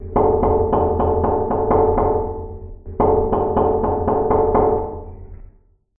Скрип и скрежет по батарее в комнате